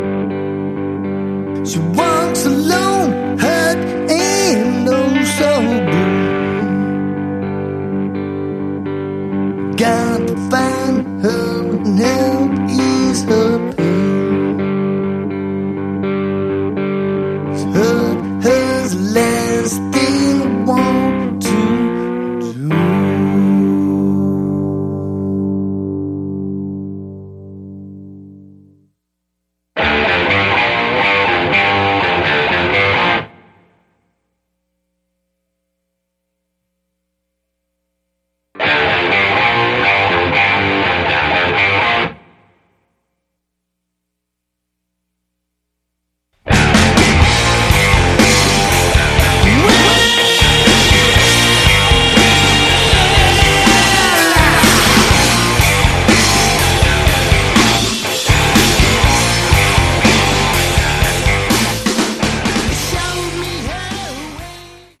Category: Hard Rock
lead vocals
guitar, vocals, keyboards
bass, vocals
drums, percussion